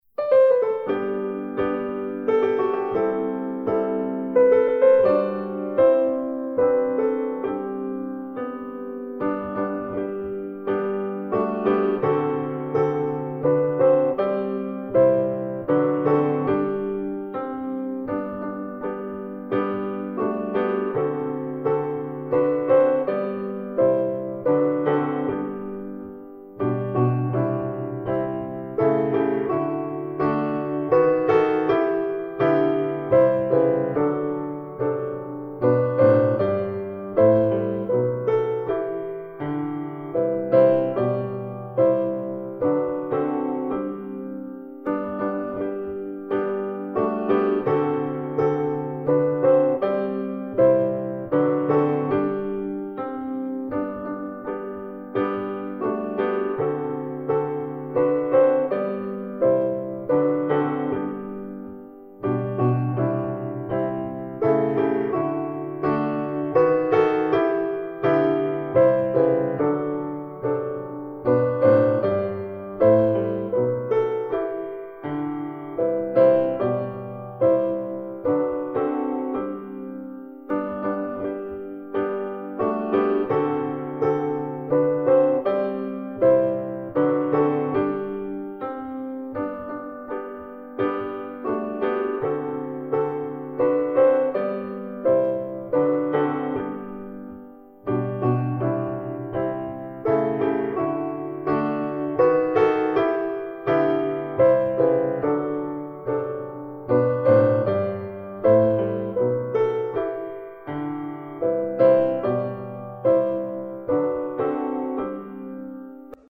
Christmas Carols
Explore 30 beloved Christmas carols accompanied by original piano recordings.